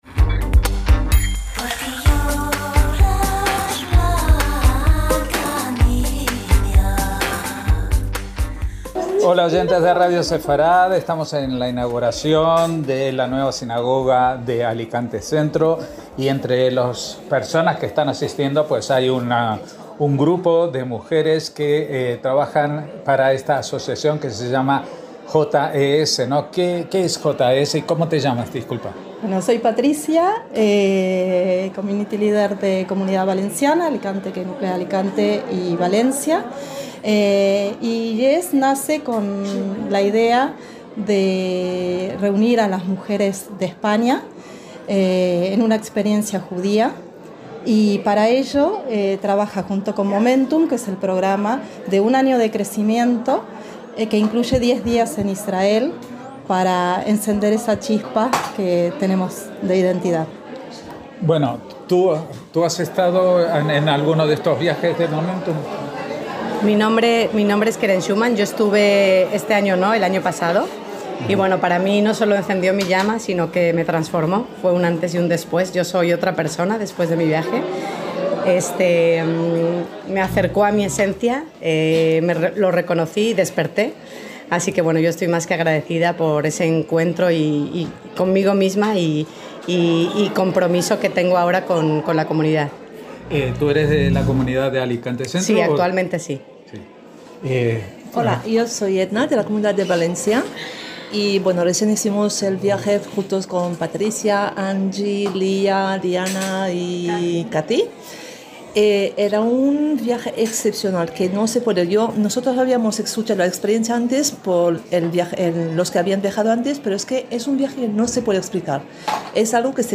NUESTRAS COMUNIDADES - En la inauguración de la nueva sinagoga y sede social de la Comunidad Judía de Alicante Centro pudimos hablar con un grupo de mujeres con muchas ganas de contarnos de su experiencia vital en el viaje Momentum que organiza la antes conocida como JWRP (siglas en inglés de Proyecto de Renacimiento de Mujeres Judías), establecida en 2008 por ocho mujeres judías que provenían de todos los ámbitos de la vida, afiliación, observancia y edad, y que buscaron una manera de empoderar a las mujeres para cambiar el mundo a través de los valores judíos.